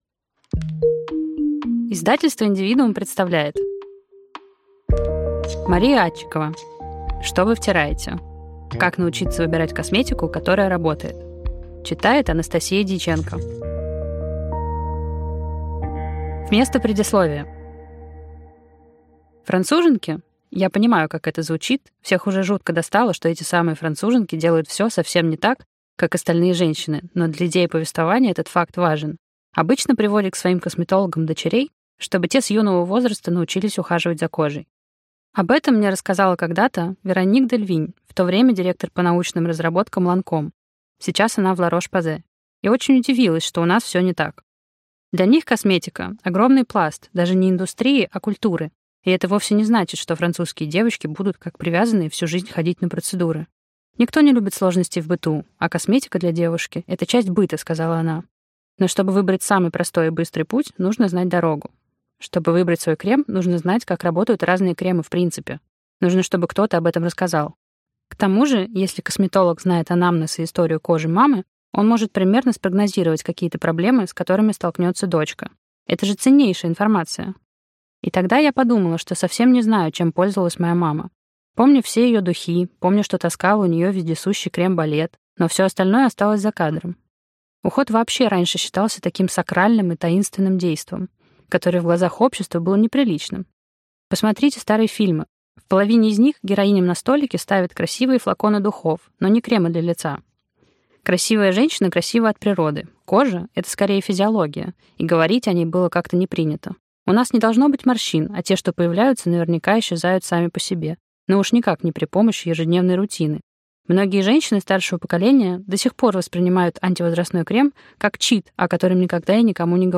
Аудиокнига Что вы втираете. Как научиться выбирать косметику, которая работает | Библиотека аудиокниг